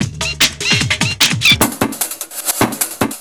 FXBEAT07-R.wav